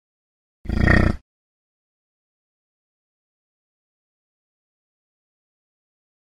Звуки буйвола
Звук, де буйвол фуркнув